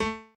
admin-fishpot/b_pianochord_v100l16o4a.ogg at main